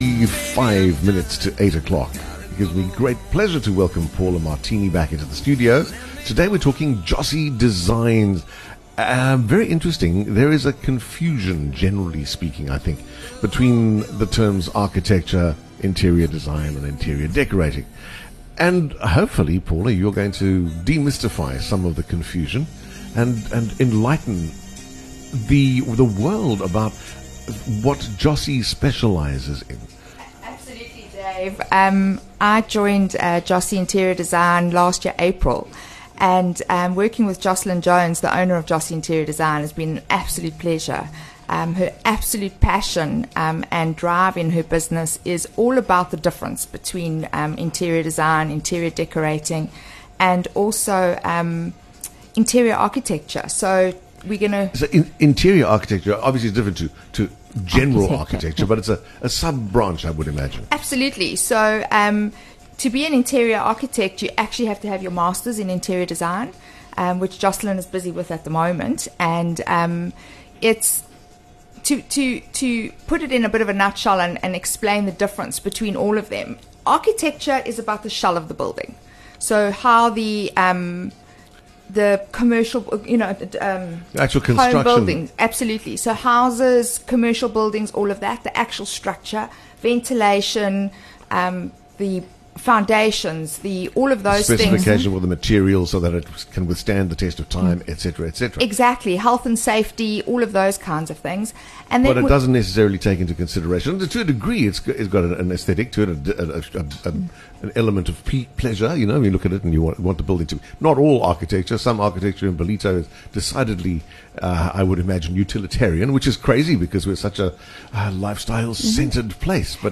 People are often confused about the difference between Architecture, Interior Design and Interior Decorating. Our purpose with these interviews is to try and help you try and make sense of it all.